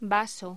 Locución: Vaso